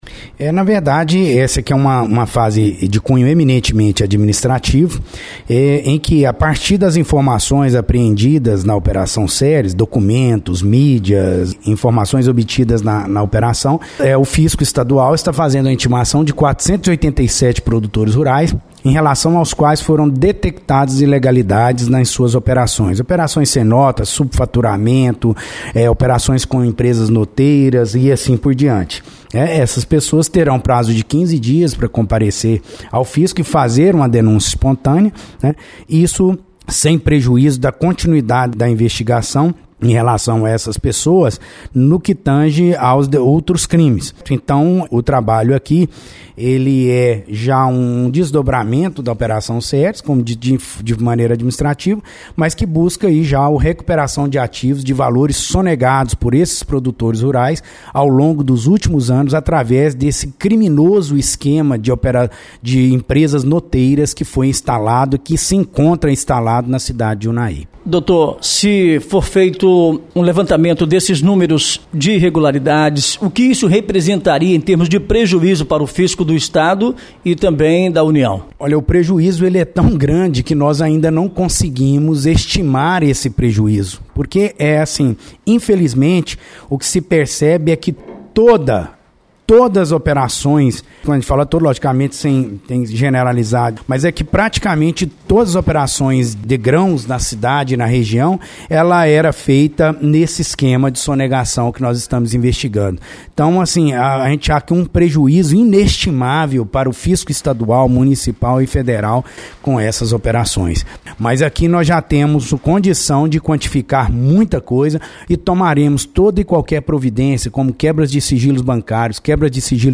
Os detalhes desta segunda fase foram apresentados em entrevista coletiva concedida pelo Dr. Genney Randro Barros de Moura, Promotor de Justiça da Coordenadoria Regional das Promotorias de Ordem Tributária do Triângulo e Noroeste Mineiro, que inicialmente disse que essa fase é mais de cunho administrativo.